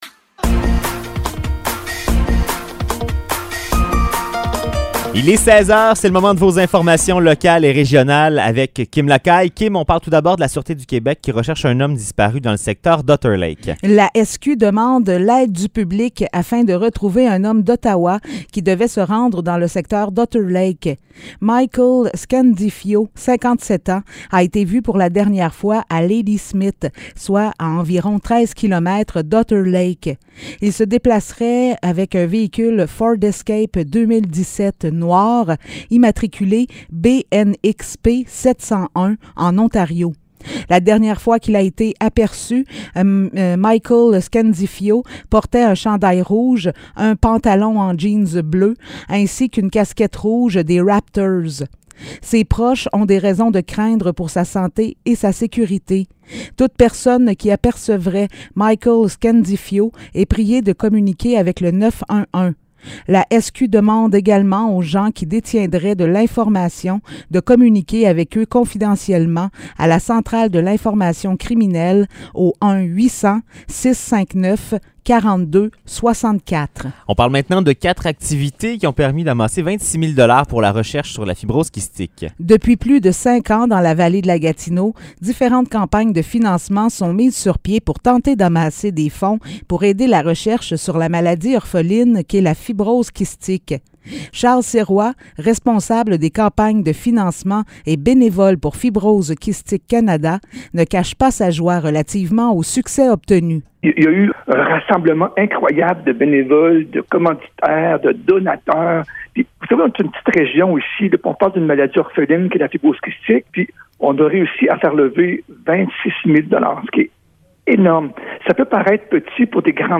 Nouvelles locales - 3 octobre 2022 - 16 h